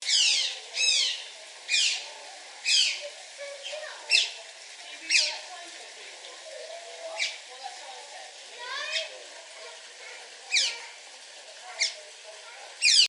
Free Animals sound effect: Otters.
Otters
yt_XVuzTBDG6rI_otters.mp3